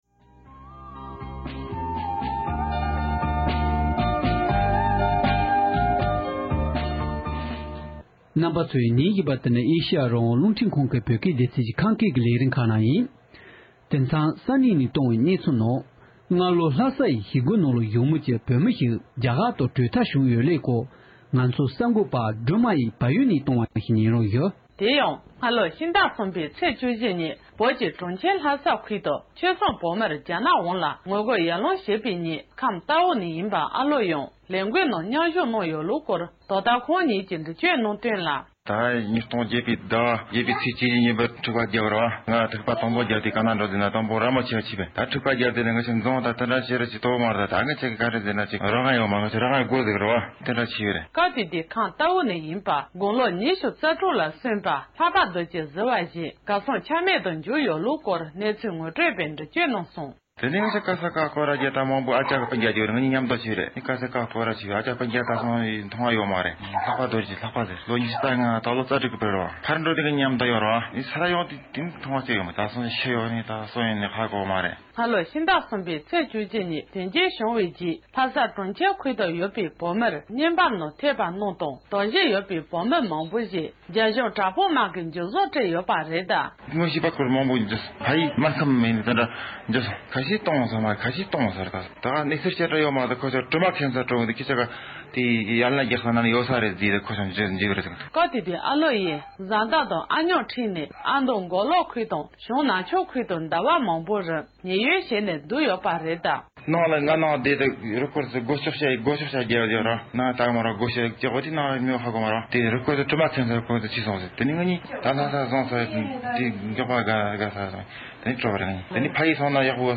ཁམས་རྟའུ་ནས་ཡིན་པའི་བོད་མི་ཞིག་གིས་ཁོང་ཕྱི་ལོ་ཉིས་སྟོང་གསུམ་ལོའི་ལྷ་སའི་ཞི་རྒོལ་ནང་མཉམ་ཞུཊ་གནང་ཡོད་པའི་སྐོར་འགྲེལ་གསུངས་བ།